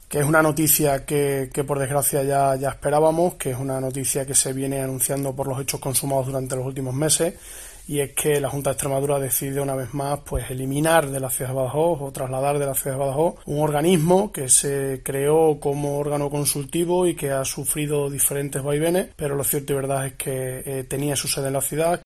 El alcalde de la ciudad, Ignacio Gragera, al que puedes escuchar en este audo, considera que la decisión, publicada ya en el DOE, va en contra de la descentralización que, según Ciudadanos, tanto defiende el PSOE a nivel nacional.